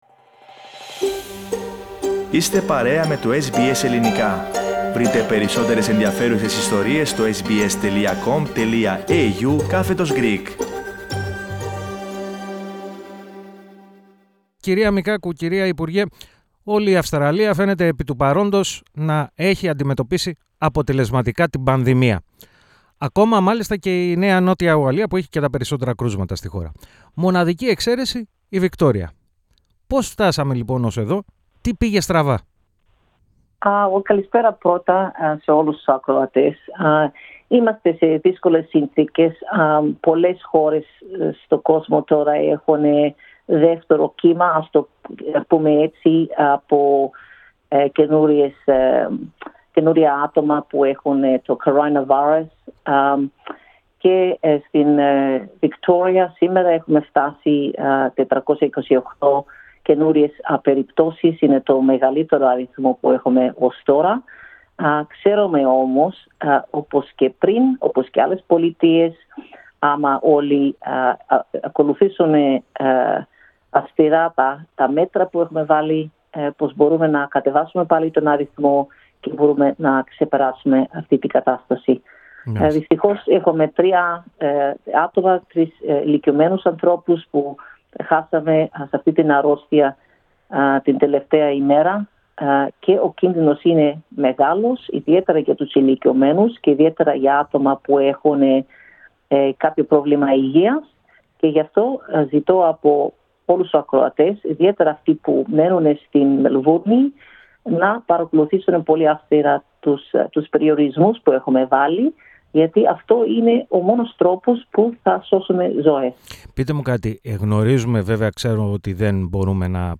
Δεν αποκλείεται μετάβαση σε στάδιο 4 lockdown: Συνέντευξη Μικάκου στο SBS Greek